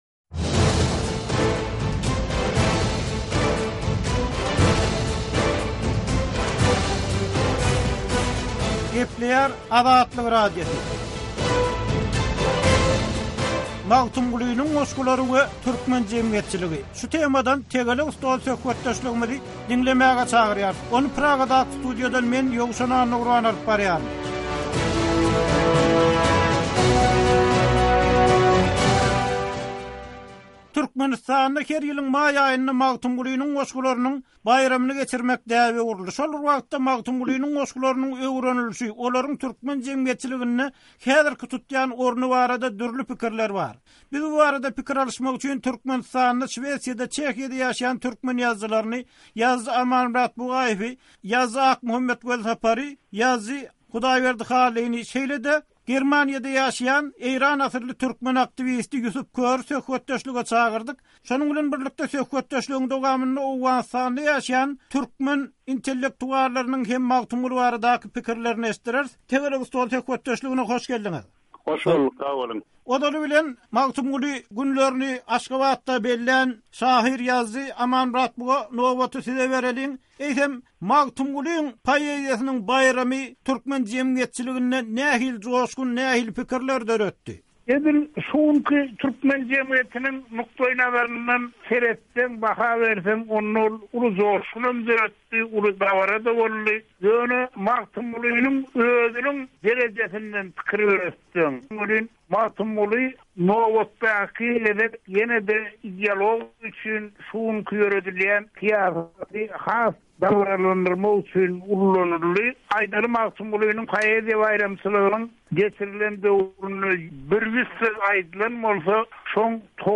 Tegelek stol: "Magtymguly" diýip, adym tutsalar...